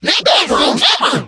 mech_mike_lead_vo_02.ogg